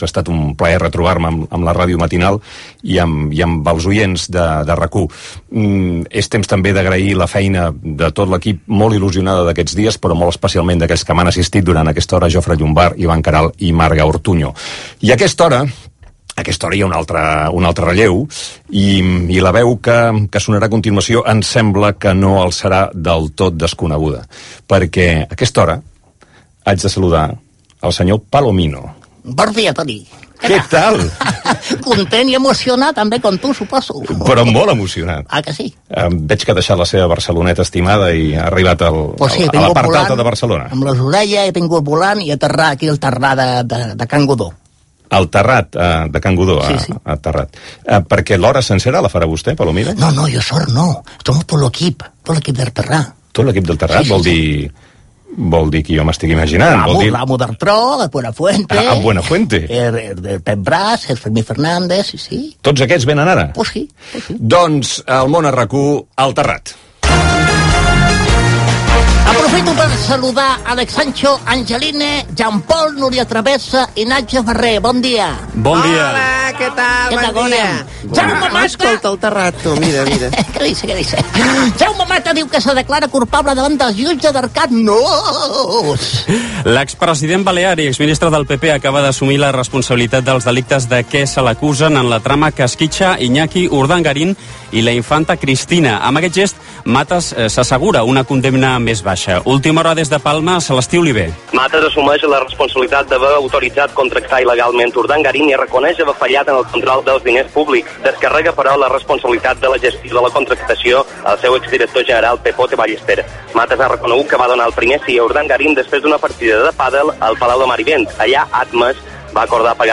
La ràdio cap per avall, que anualment fa RAC 1, amb motiu del Dia mundial de la Ràdio. Antoni Bassas dona pas al personatge "Palomino" de "El Terrat", Butlletí de notícies.
Entreteniment
FM